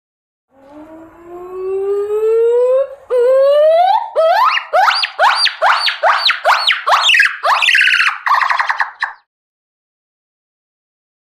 GibbonCallLoudWail AT052101
Gibbon Call. Loud Wailing Call Which Ascends And Descends In Pitch. Close Perspective.